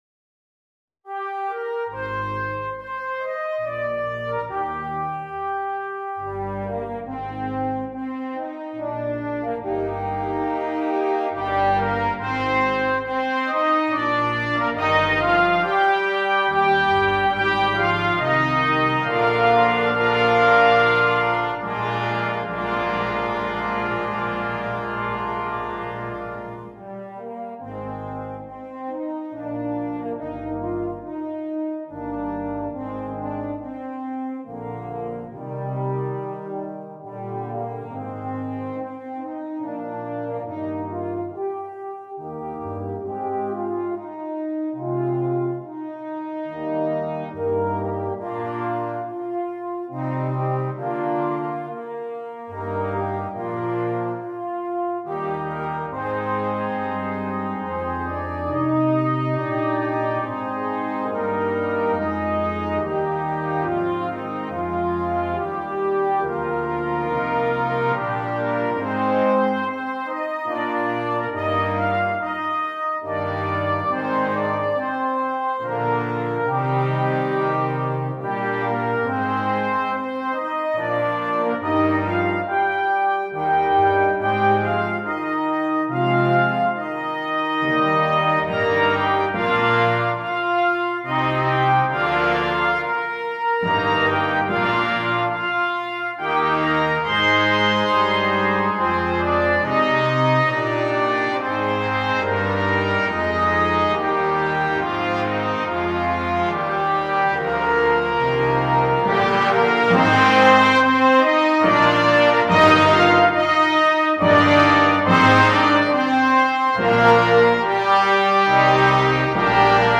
2. Brass Band
Full Band
without solo instrument
Classical
Music Sample